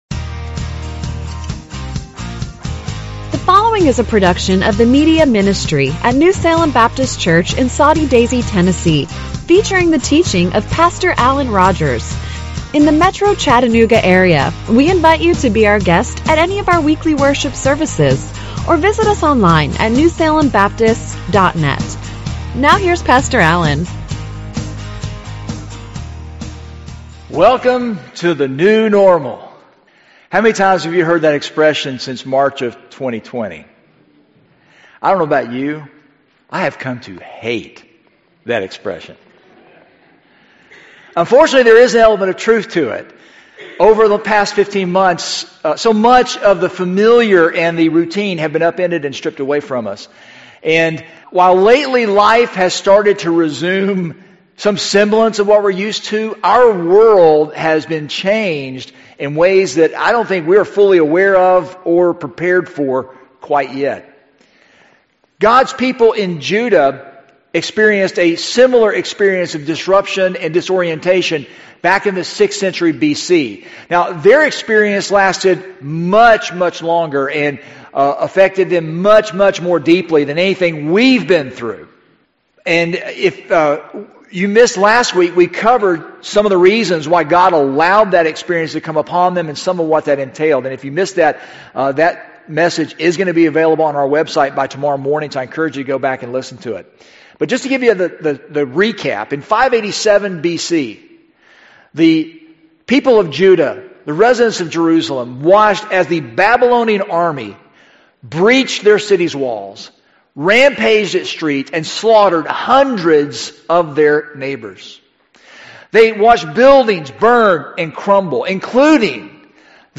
Listen to a Recent Sermon Subscribe to sermon Podcast RSSSubscribe to iTunes podcast - Page 9